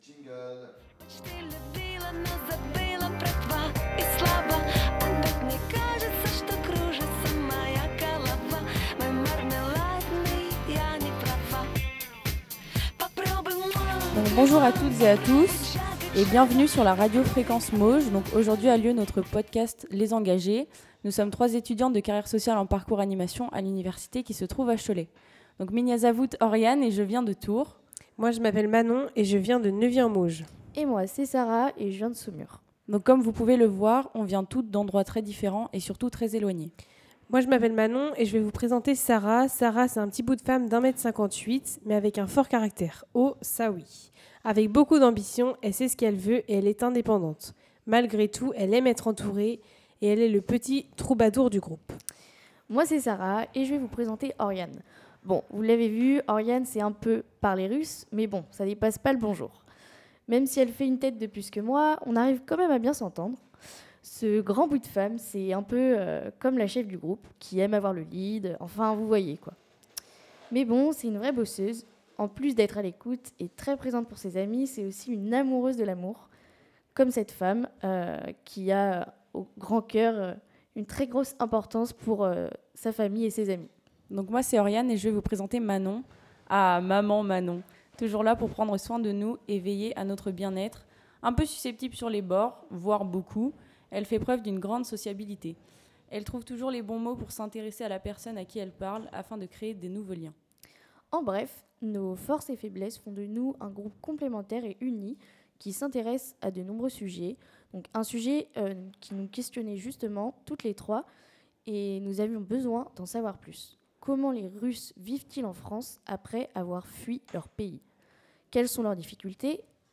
Elle revient sur les défis de l’intégration, son attachement à ses racines et la manière dont elle a construit une nouvelle vie. Un témoignage sincère et émouvant, à ne pas manquer.